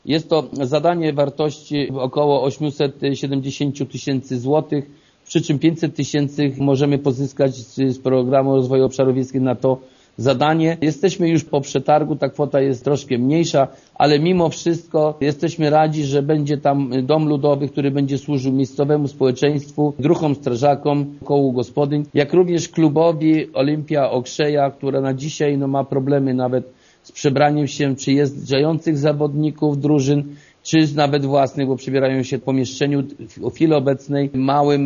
„Najpoważniejszą inwestycją jest trwająca od kilku tygodni budowa domu ludowego w Okrzei” - mówi wójt Gminy Krzywda Jerzy Kędra: